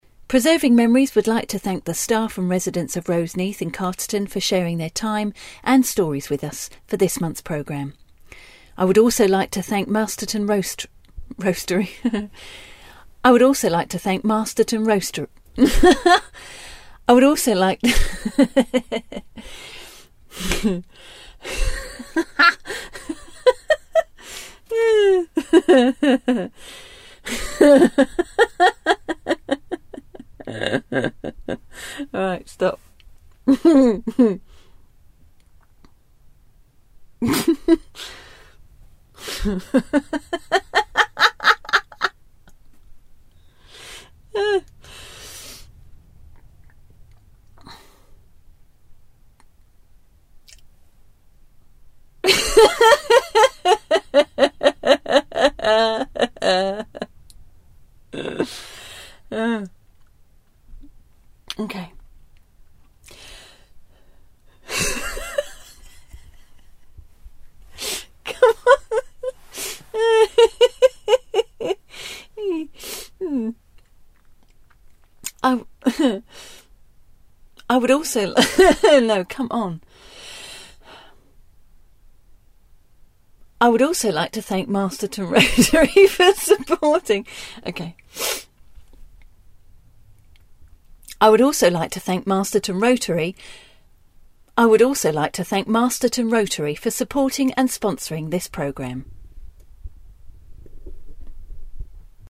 Maybe it was the unusual recording ‘studio’ (I was in the cupboard under the stairs); maybe it was the lateness of the hour; maybe I was channelling these wonderful fellas!
So great to hear you giggling away for no apparent reason.